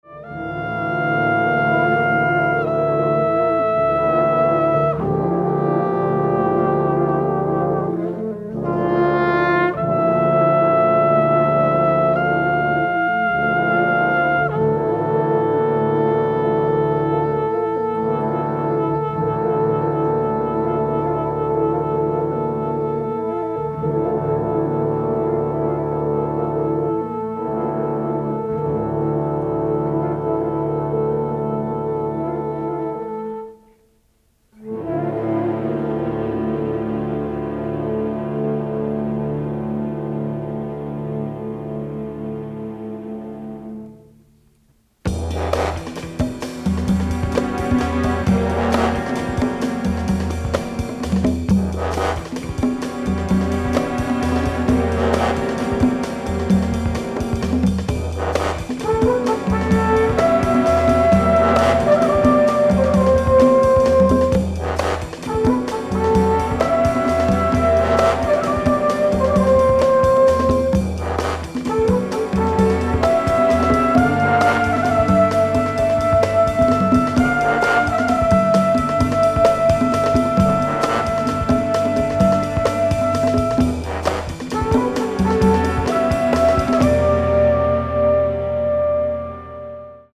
swinging orchestral soundscape